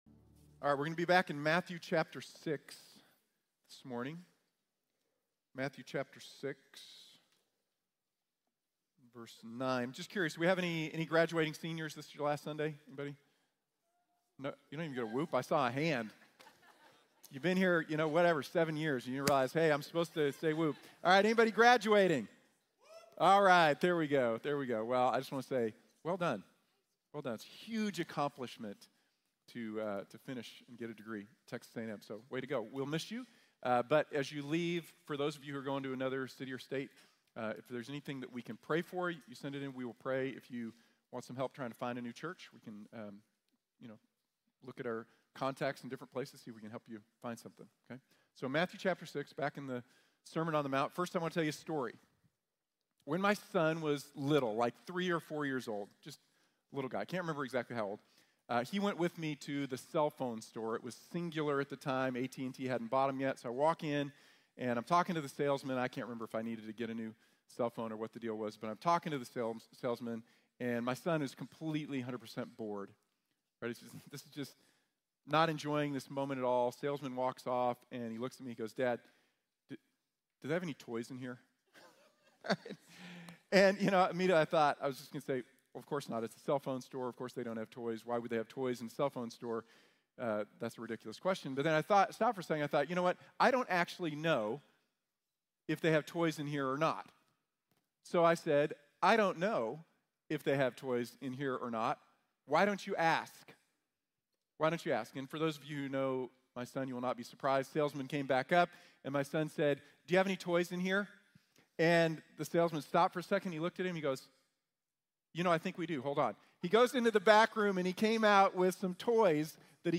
Ask… and keep on asking | Sermon | Grace Bible Church